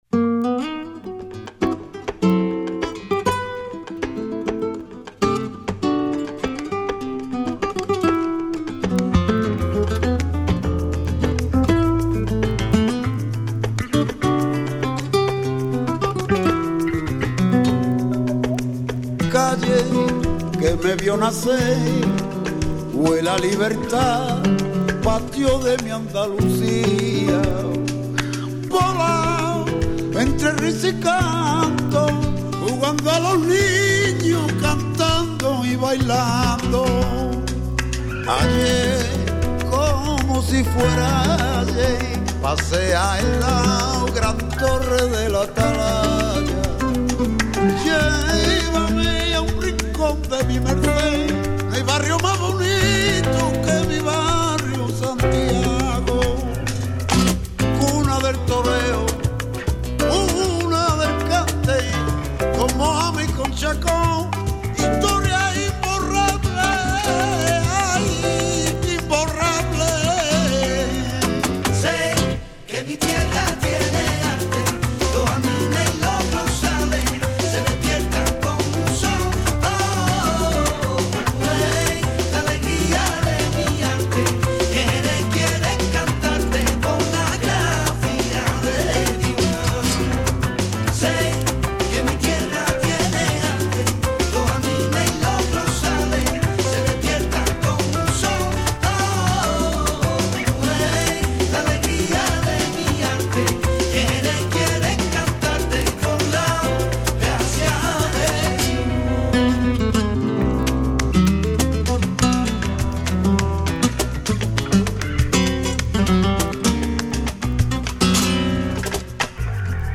Escucha aquí la entrevista a José Mercé
José Mercé visitó nuestro estudios junto a Tomatito
entrevista-jose-merce-y-tomatito.mp3